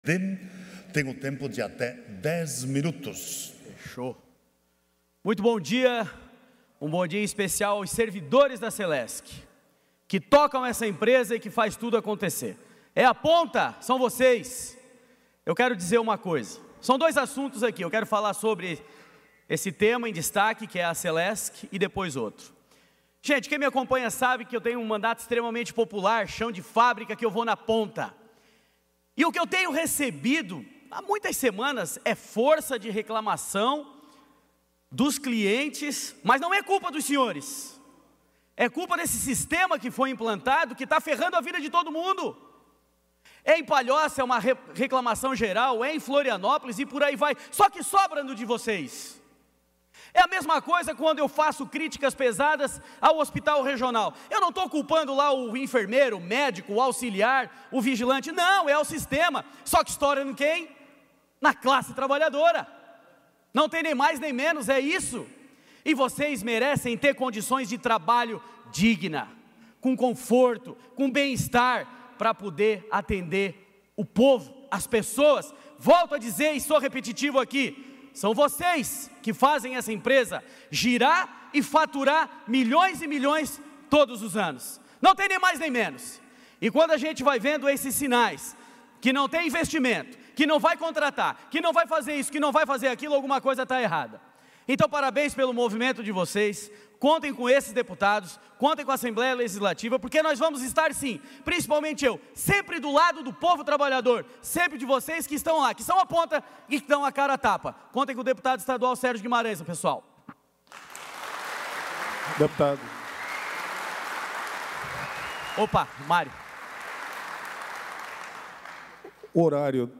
Pronunciamentos da sessão ordinária desta quinta-feira (27)
- deputado Sérgio Guimarães (União);